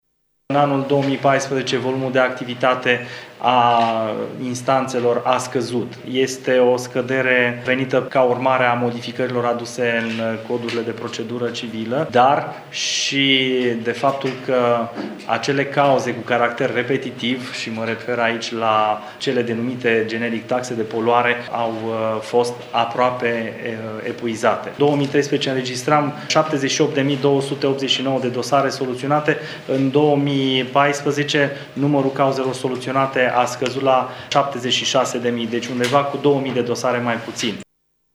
Anunţul a fost făcut cu ocazia prezentării bilanţului instanţei.
Cu ocazia şedinţei de bilanţ s-a constat, de asemenea, că volumul de activitate al instanţelor mureşene a scăzut în anul 2014 ceea ce a dus la creşterea calităţii actului de justiţie, a subliniat Dima: